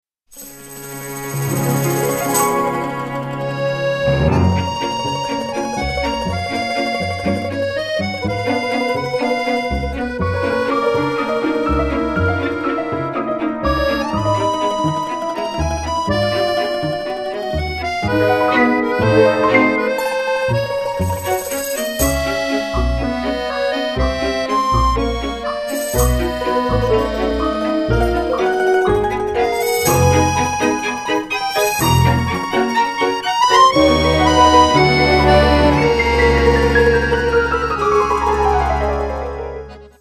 Piano
Violin
Accordion